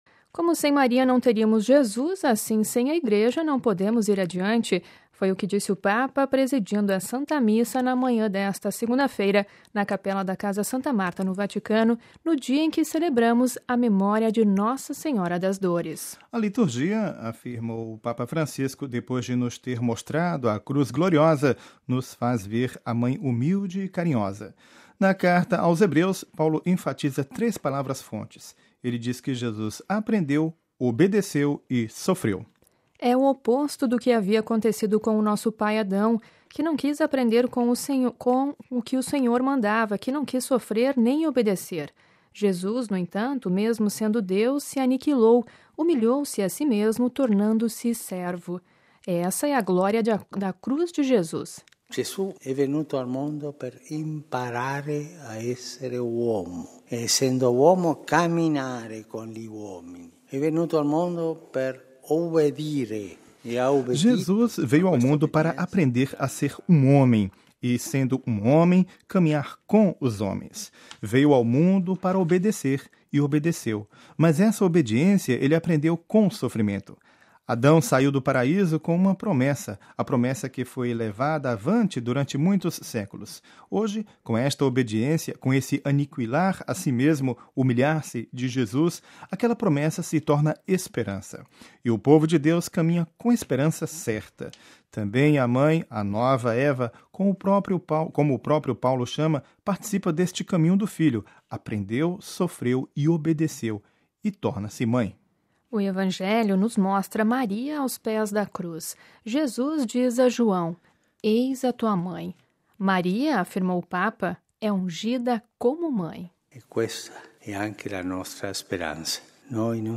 É o que disse o Papa presidindo a Santa Missa na manhã desta segunda-feira, na capela da Casa Santa Marta, no dia em que celebramos a memória de Nossa Senhora das Dores.